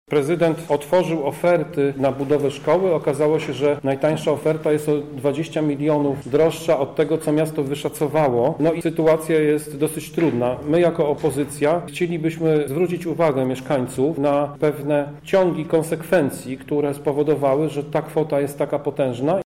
Z wyjaśnieniami prezydenta, na temat zwiększającego się budżetu budowy, nie zgadza się radny Prawa i Sprawiedliwości Tomasz Pitucha